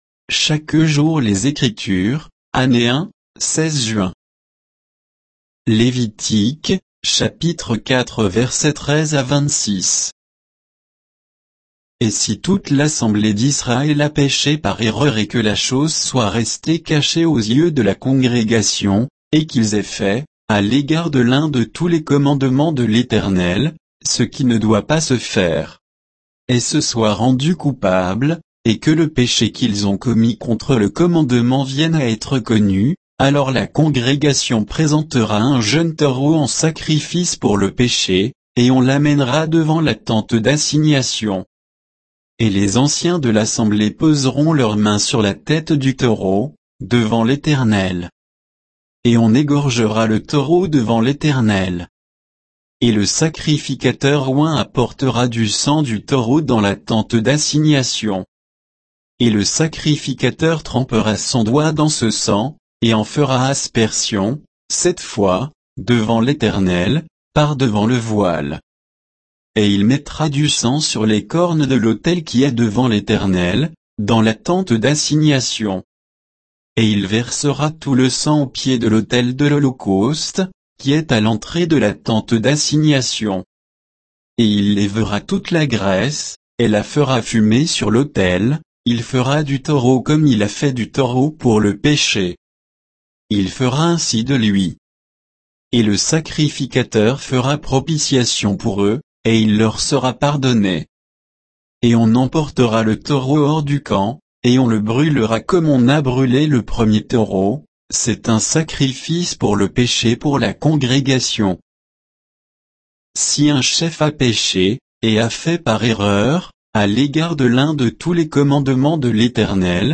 Méditation quoditienne de Chaque jour les Écritures sur Lévitique 4